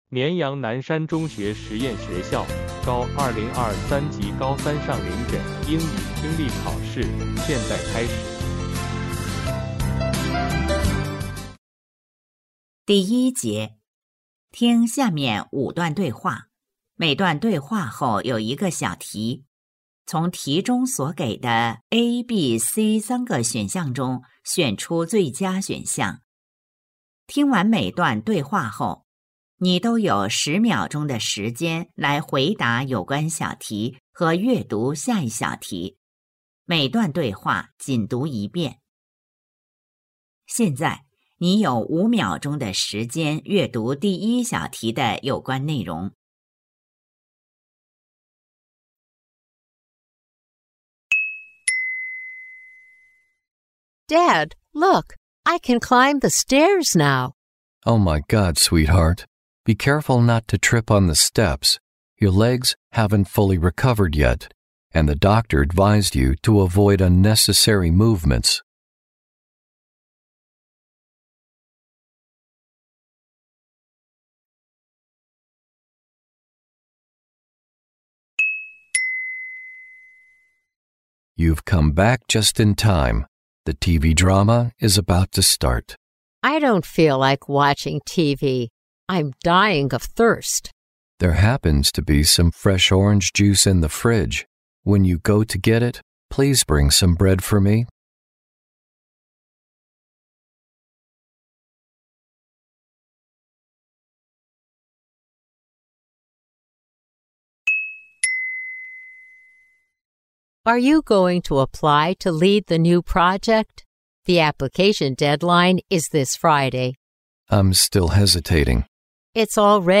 绵阳南山中学实验学校2026届高三上学期9月月考英语听力.mp3